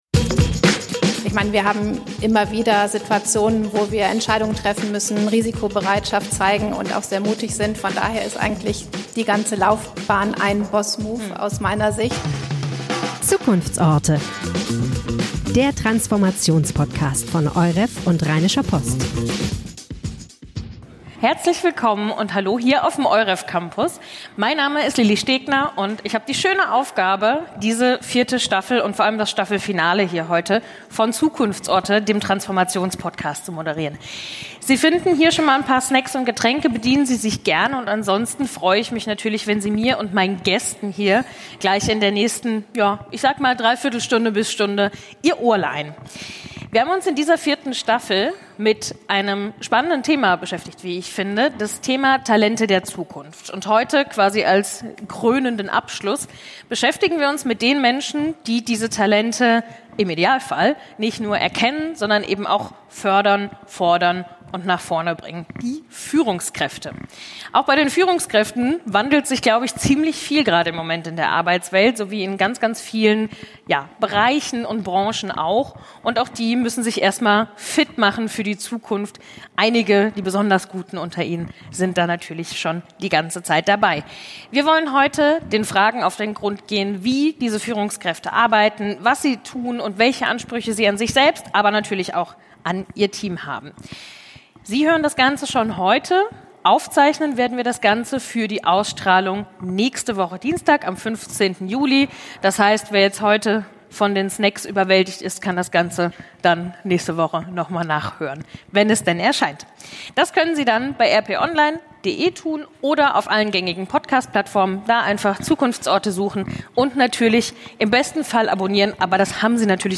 LIVE: Boss Moves – Was macht eine Führungskraft der Zukunft aus?